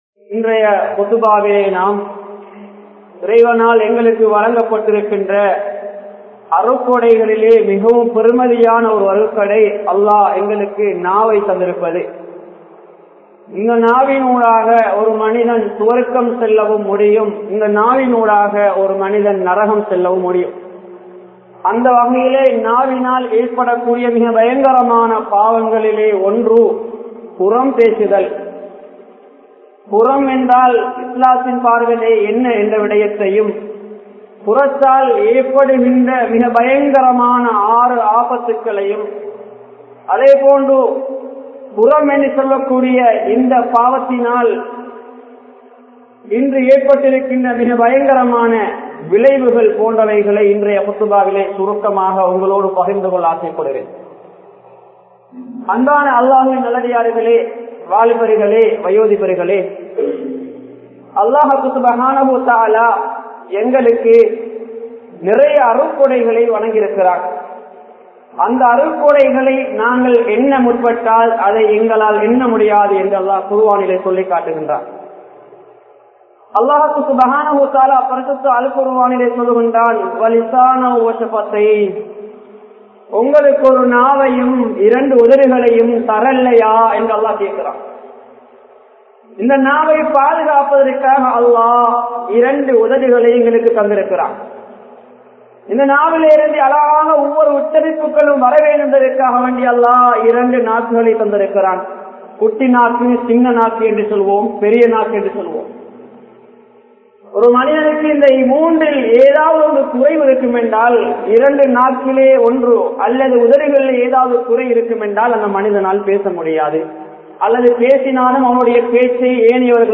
Puram Peasuvathin Vilaivuhal (புறம் பேசுவதின் விளைவுகள்) | Audio Bayans | All Ceylon Muslim Youth Community | Addalaichenai
Ameeniyya Jumua Masjidh